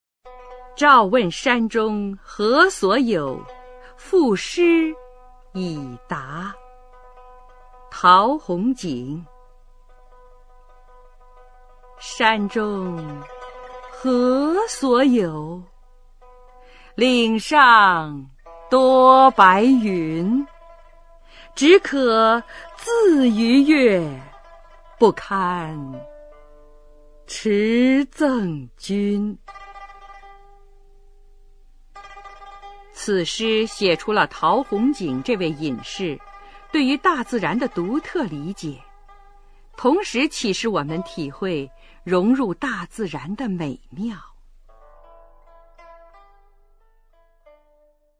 [魏晋诗词诵读]陶宏景-诏问山中何所有赋诗以答 古诗朗诵